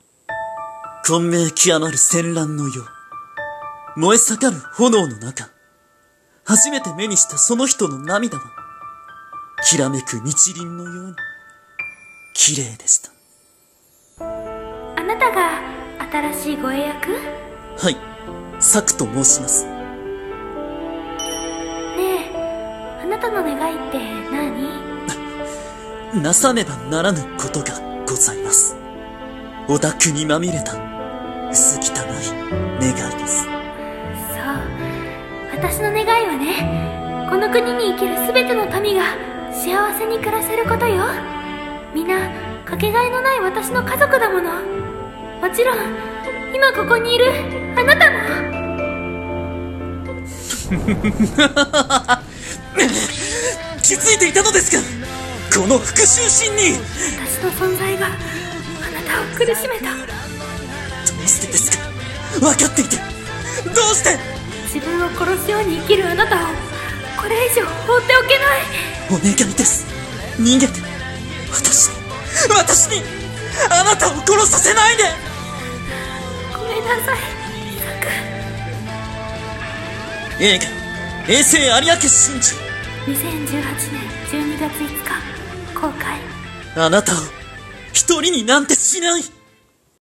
【CM風声劇台本】